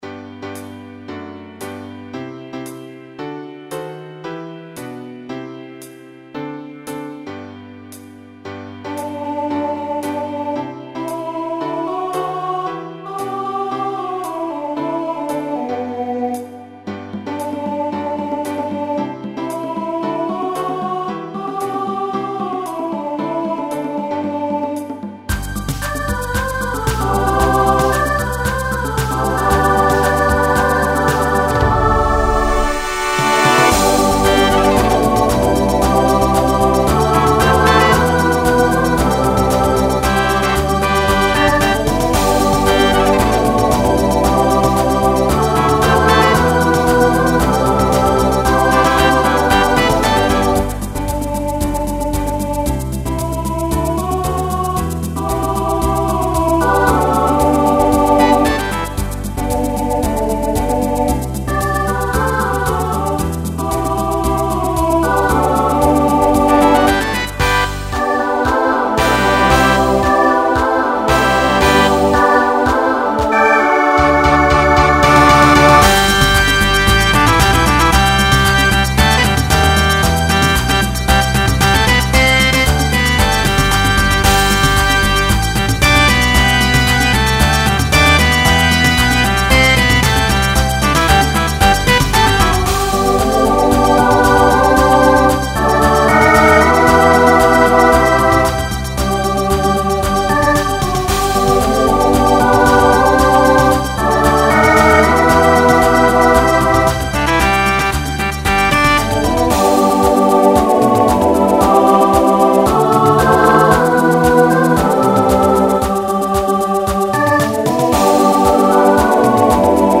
Genre Pop/Dance Instrumental combo
Mid-tempo Voicing SATB